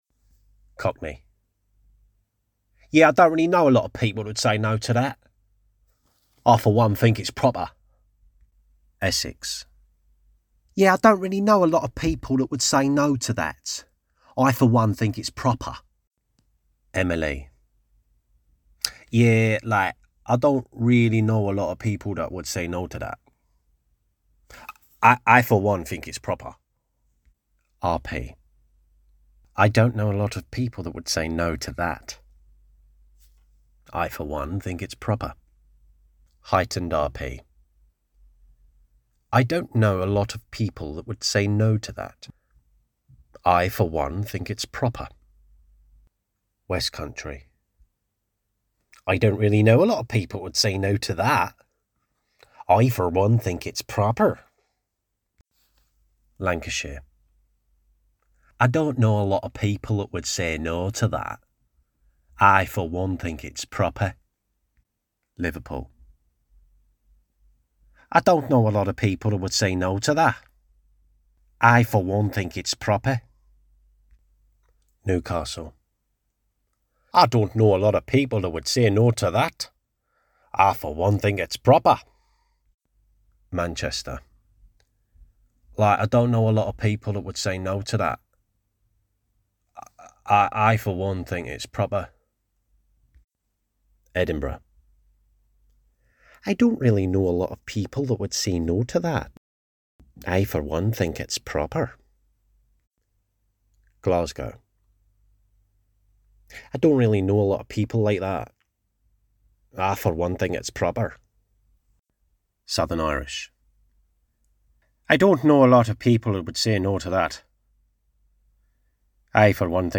Cockney, Confident, Gravitas, Cheeky Chappie, Deep